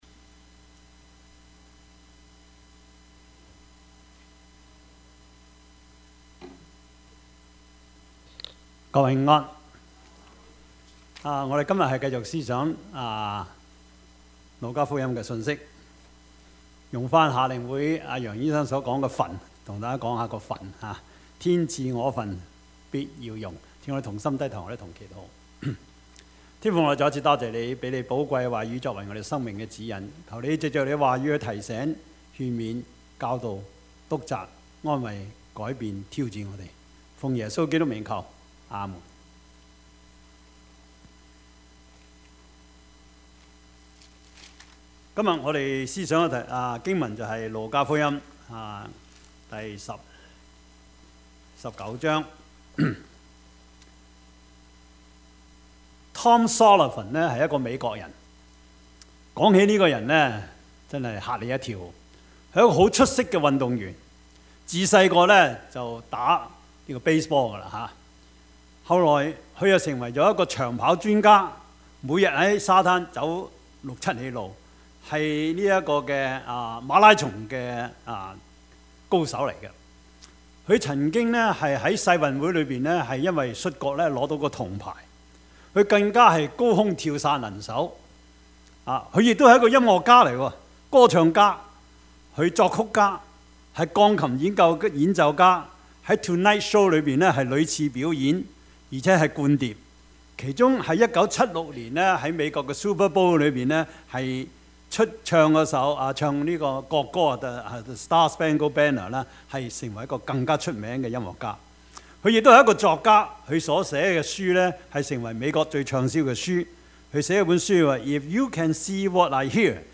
Passage: 路 加 福 音 19:11-27 Service Type: 主日崇拜
Topics: 主日證道 « 還要游移不定嗎?